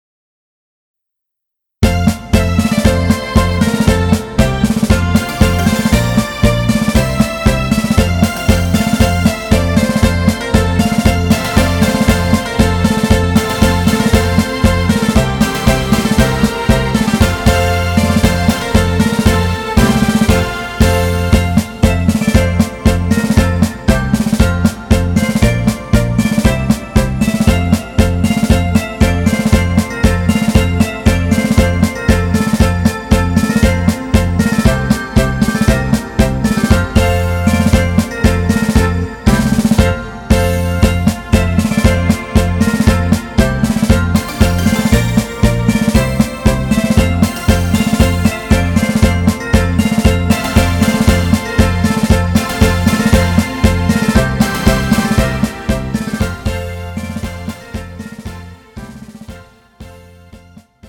MR 고음질 반주 다운로드.